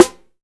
SNARE.62.NEPT.wav